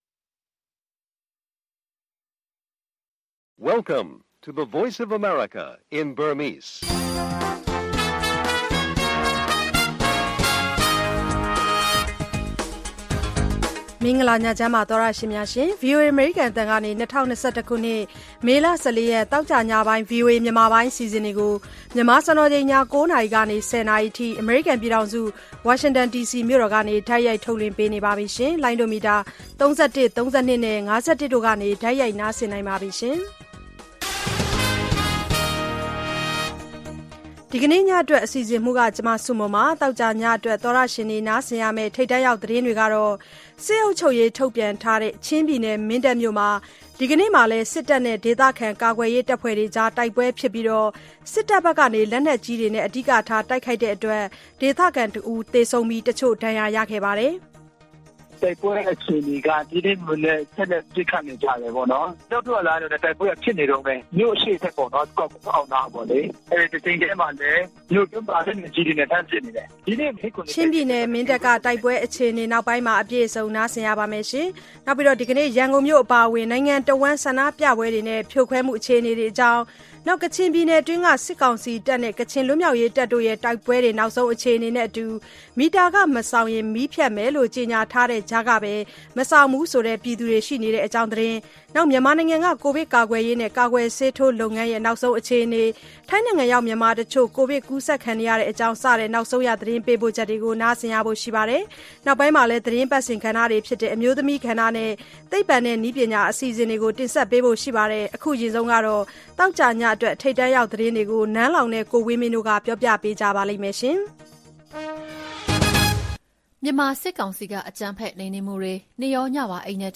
မြန်မာနဲ့ နိုင်ငံတကာ ထိပ်တန်းသတင်းနဲ့ သတင်းပေးပို့ချက်များ၊ အမျိုးသမီးကဏ္ဍနဲ့ သိပ္ပံနဲ့နည်းပညာ အပတ်စဉ်ကဏ္ဍများအပါအဝင် သောကြာည ၉း၀၀-၁၀း၀၀ နာရီ ရေဒီယိုအစီအစဉ်